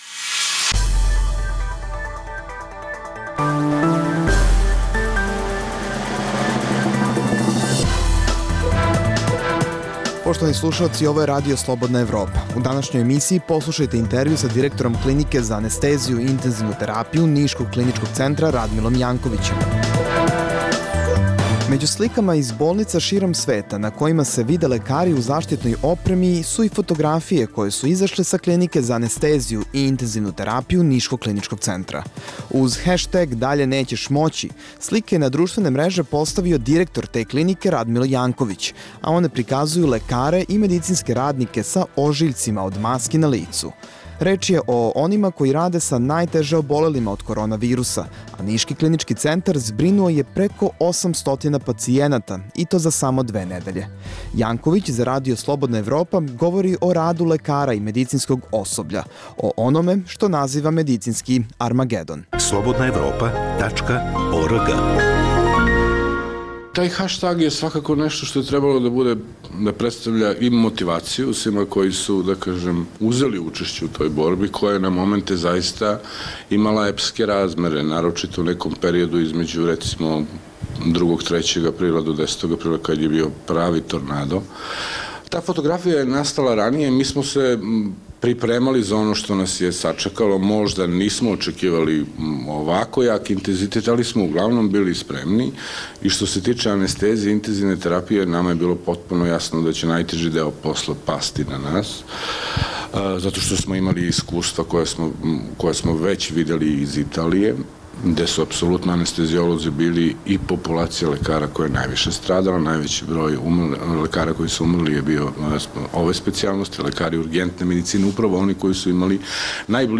u kojem ugledni sagovornici iz regiona razmatraju aktuelne teme. Drugi dio emisije čini program "Pred licem pravde" o suđenjima za ratne zločine na prostoru bivše Jugoslavije.